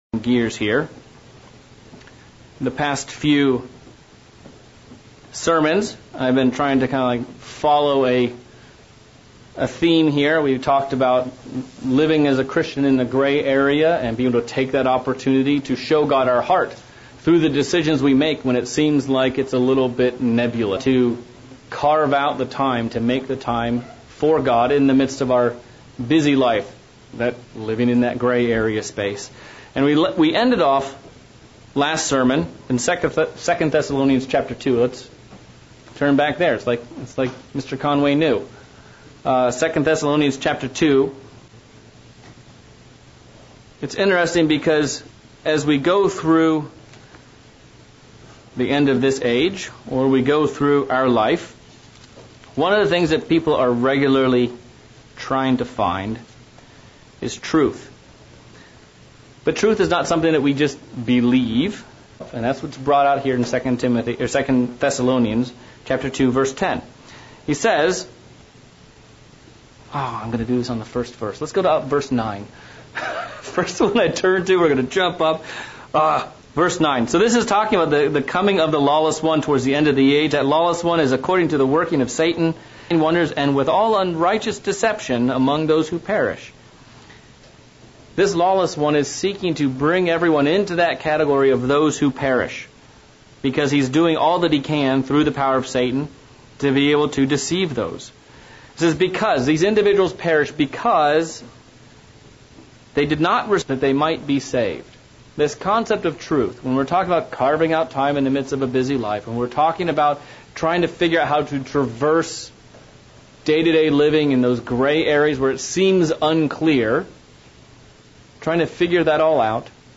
Thessalonians states that one of the keys to strong deception is not receiving the love of the truth and having pleasure in unrighteousness. This sermon covers 5 keys to help us stand strong and hava a vibrant love for the truth.